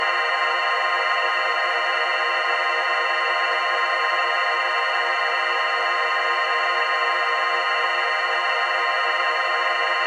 beacon.wav